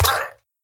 Sound / Minecraft / mob / villager / death.ogg
Current sounds were too quiet so swapping these for JE sounds will have to be done with some sort of normalization level sampling thingie with ffmpeg or smthn 2026-03-06 20:59:25 -06:00 10 KiB Raw History
death.ogg